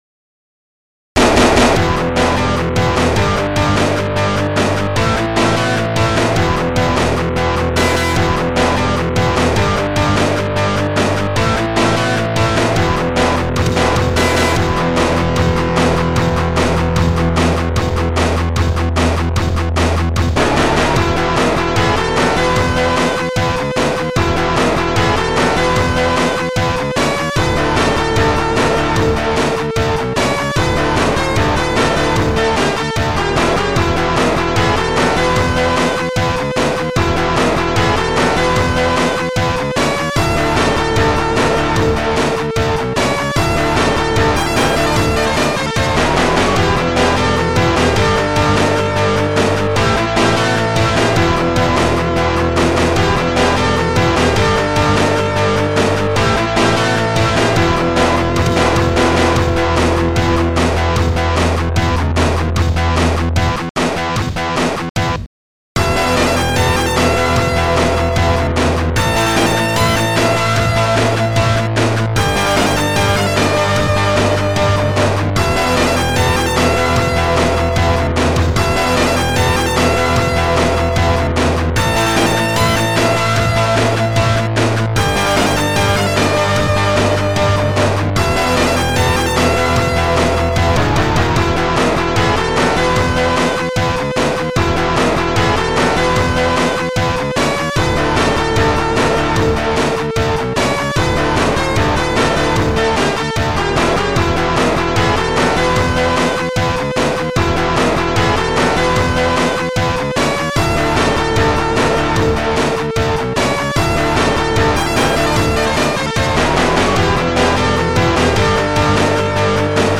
Protracker Module
st-99:akaisnare4
st-99:metalguitar